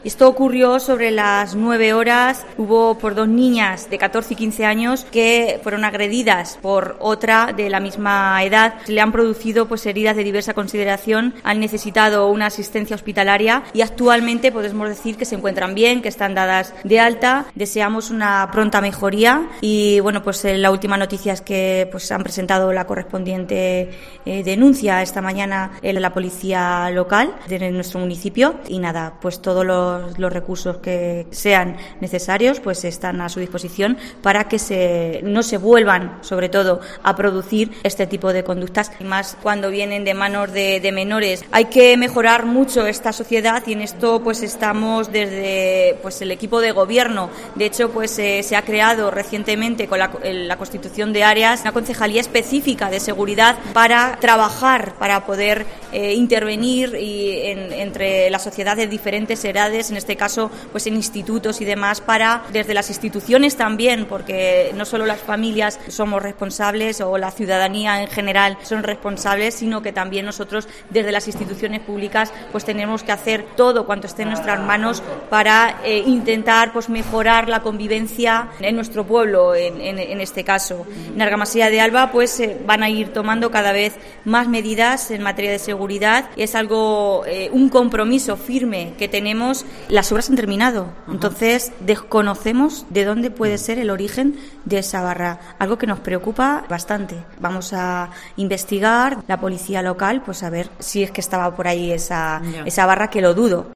Sonia González, alcaldesa de Argamasilla de Alba